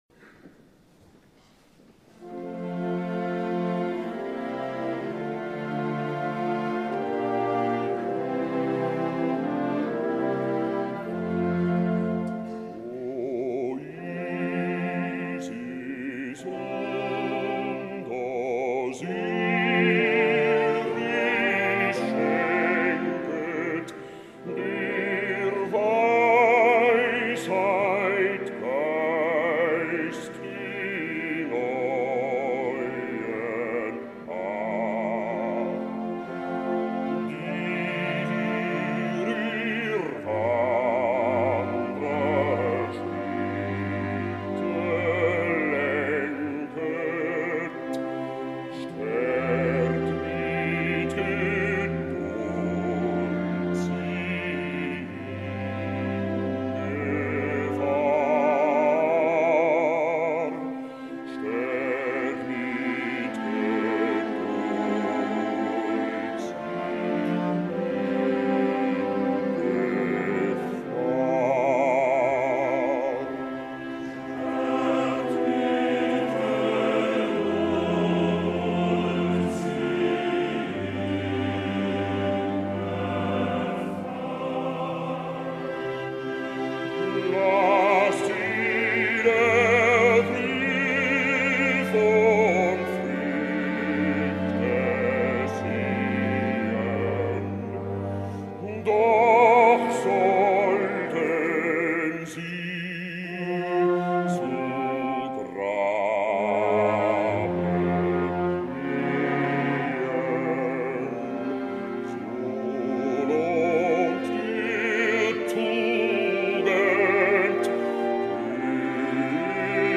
Tipos de Bajos - Cantá Lírico
Bajo-Profundo-Kurt-Moll-sing-O-isis-und-Osiris_0BdhZ3NhKdo.mp3